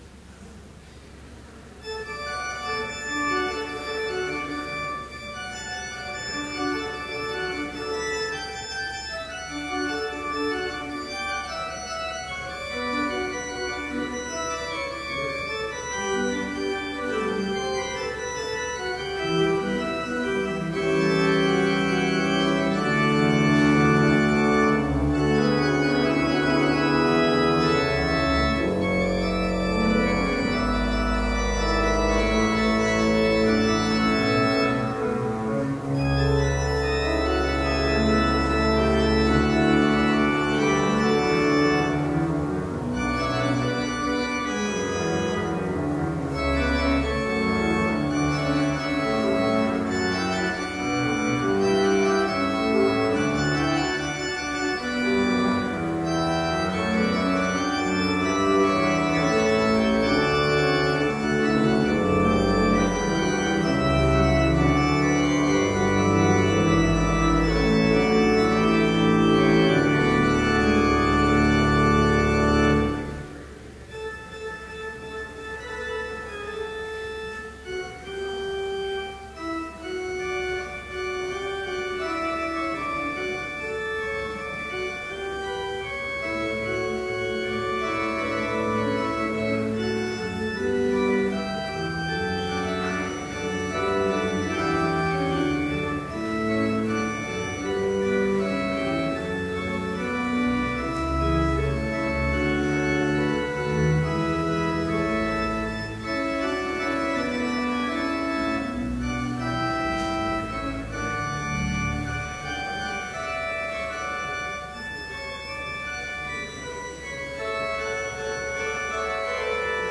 Complete service audio for Fall Festival Matins - September 23, 2012
(All may join with the choir to sing Hymn 69, vv. 1 & 8)